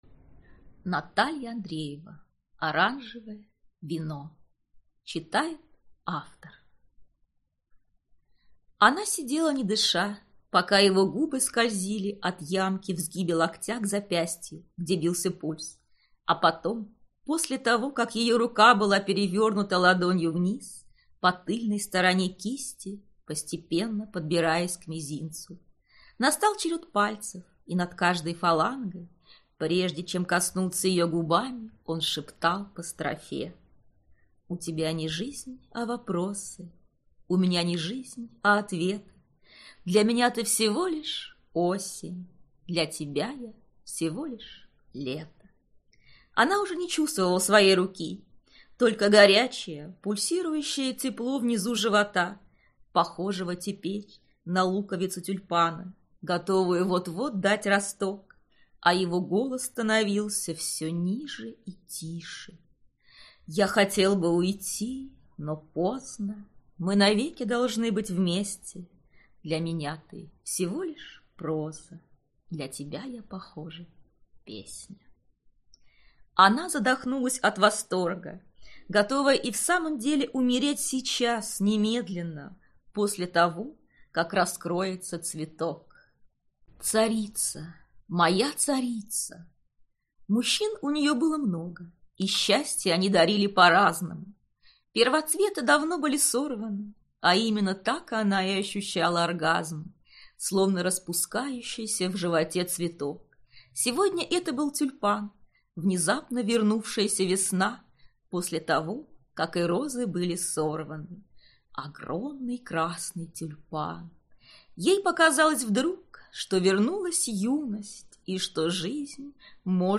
Аудиокнига Оранжевое вино | Библиотека аудиокниг